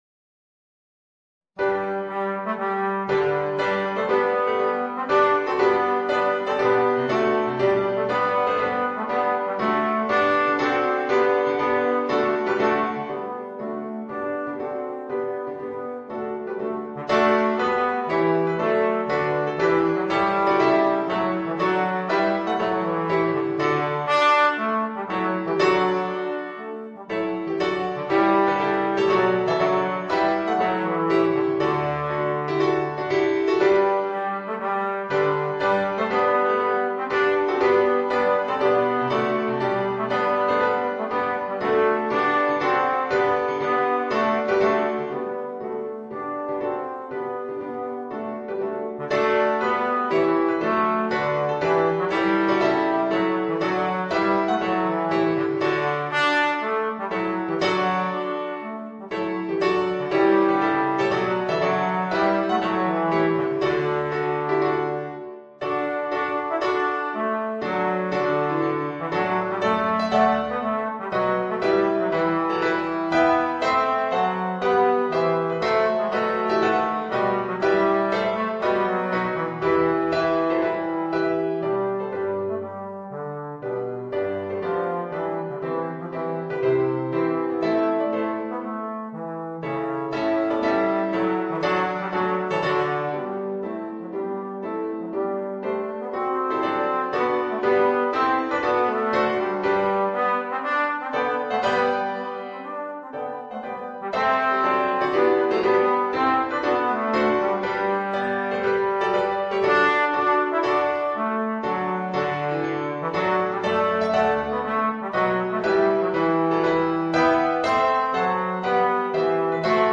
Voicing: Trombone and Organ